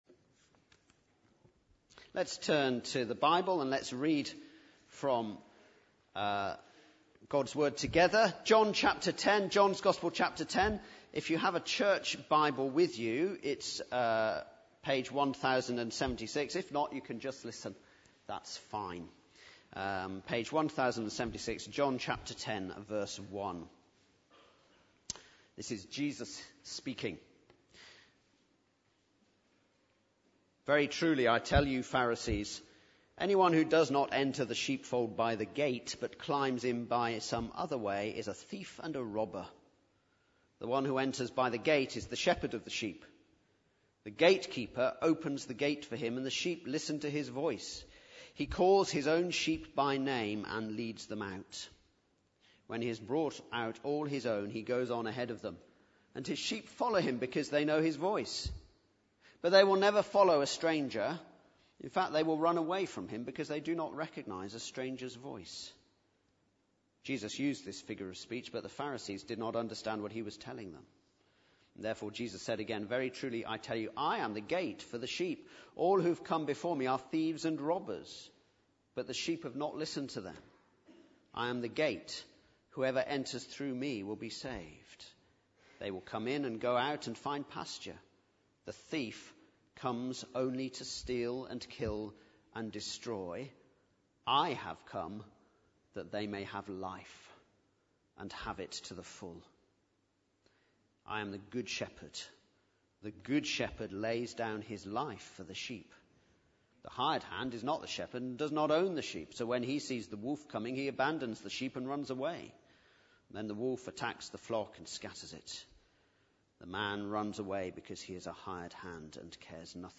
Baptismal Service – September 2014